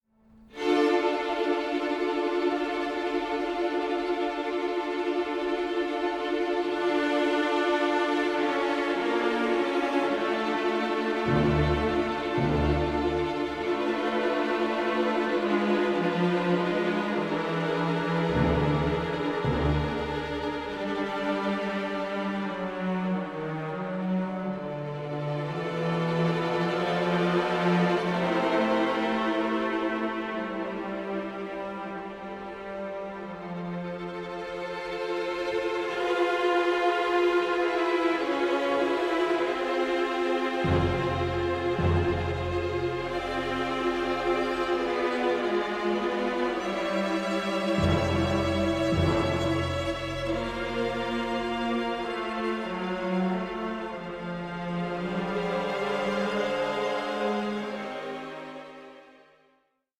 orchestral suites